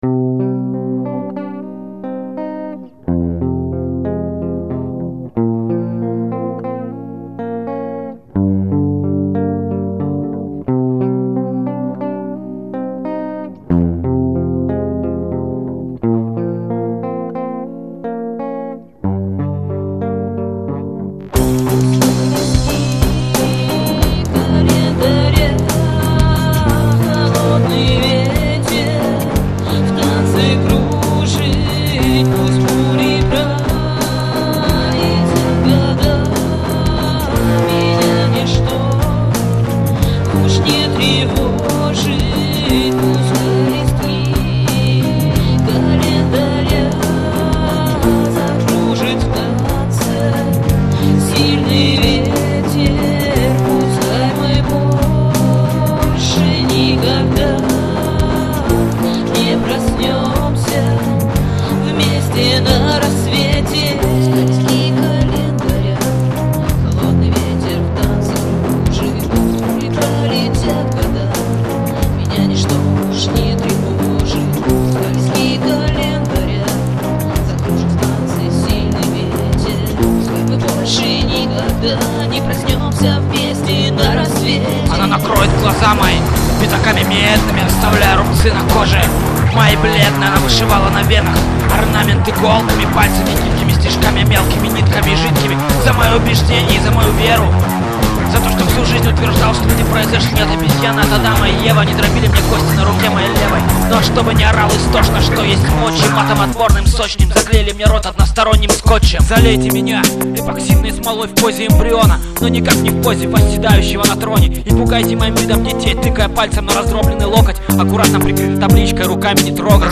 • Жанр: Альтернативная